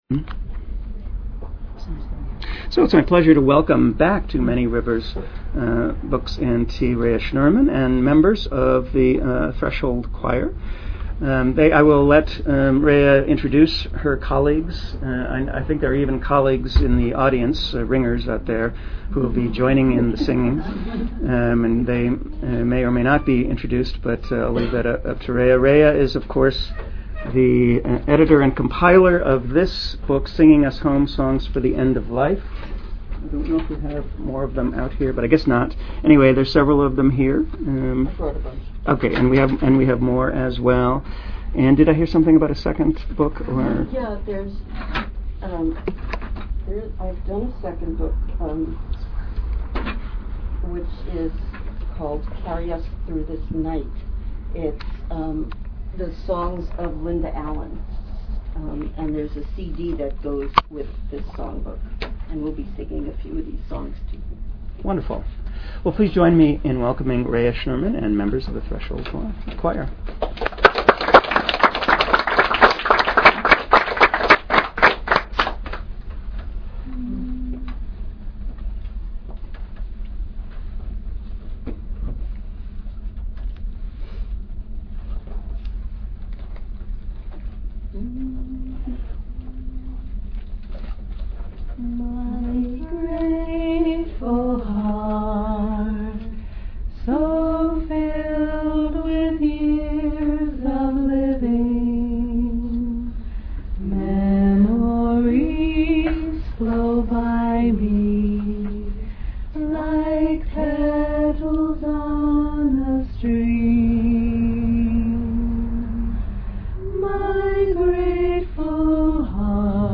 Archive of an event at Sonoma County's largest spiritual bookstore and premium loose leaf tea shop.
They will be singing and teaching songs from the book.